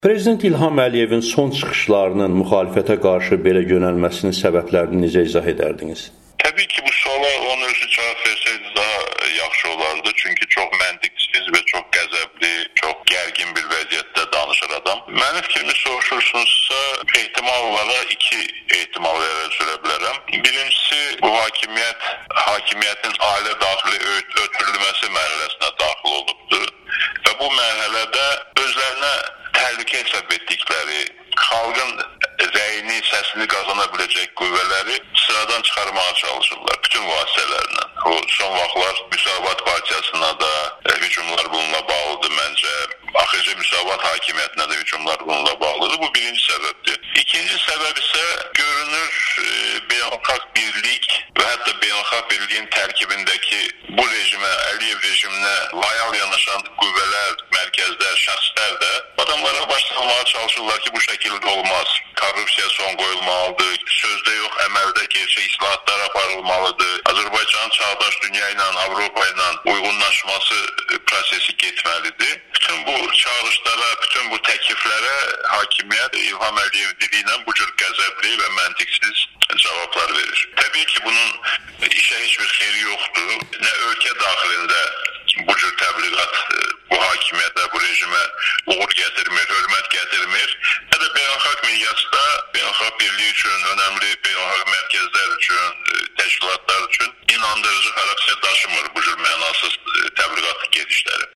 Milli Strateji Düşüncə Mərkəzinin rəhbəri, parlamentin keçmiş sədri İsa Qəmbər Amerikanın Səsinə müsahibəsində İlham Əliyevin son çıxışlarını `gərgin` adlandırıb.